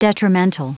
Prononciation audio / Fichier audio de DETRIMENTAL en anglais
Prononciation du mot : detrimental